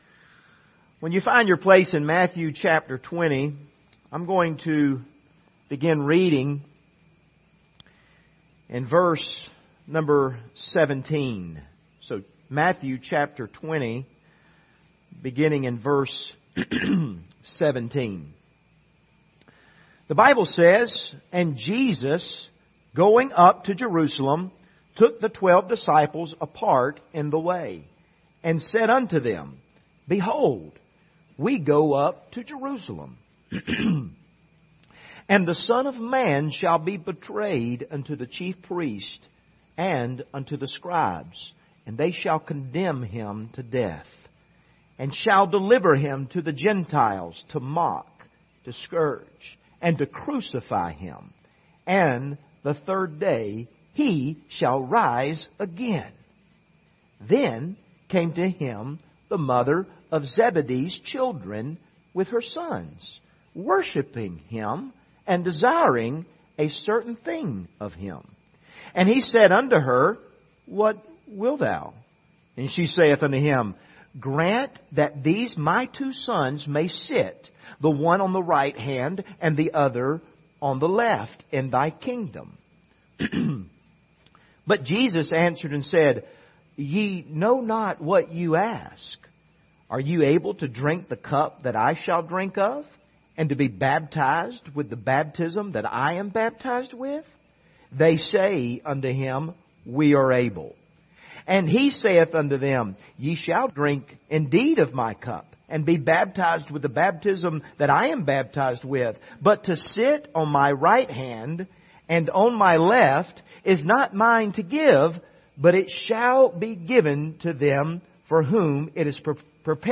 Passage: Matthew 20:17-28 Service Type: Sunday Morning View the video on Facebook Topics